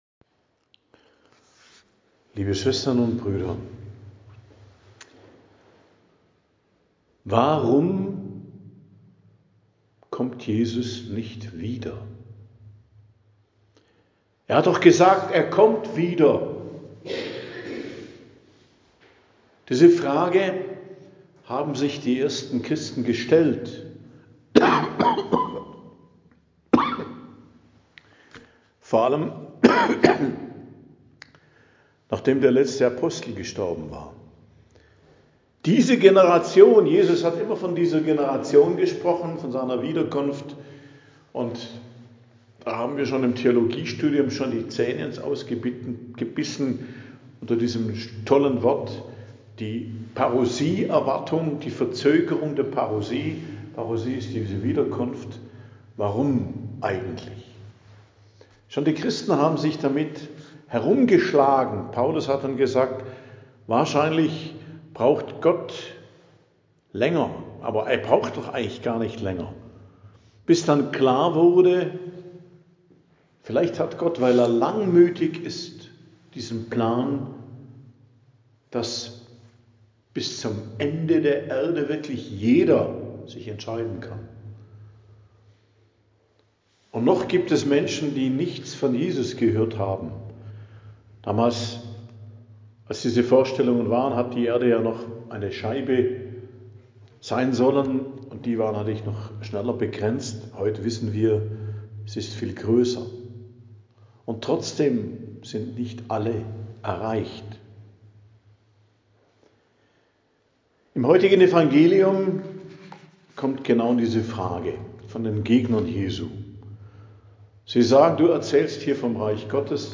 Predigt am Donnerstag der 32. Woche i.J., 13.11.2025 ~ Geistliches Zentrum Kloster Heiligkreuztal Podcast